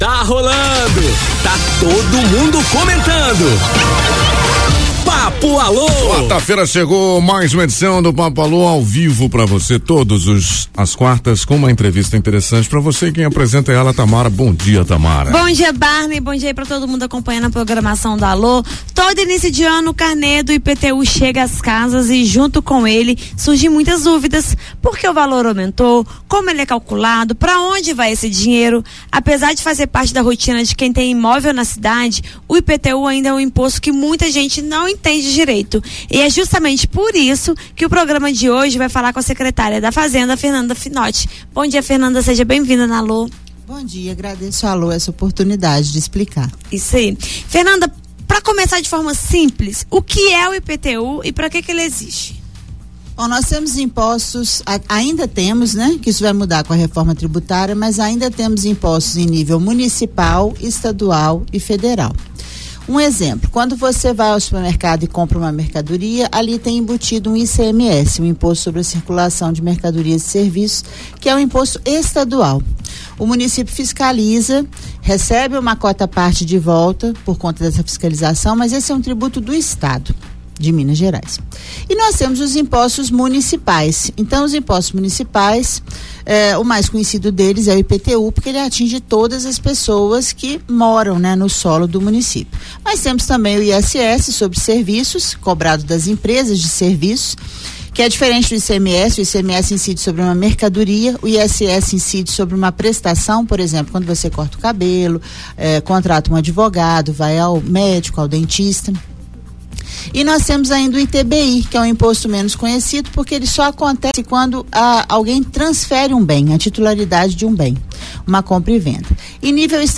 Apesar de fazer parte da rotina de quem tem imóvel na cidade, o IPTU ainda é um imposto que muita gente não entende direito. E é justamente por isso que o programa de hoje vai falar com a Secretária da Fazenda – Fernanda Finnoti